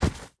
drop2.wav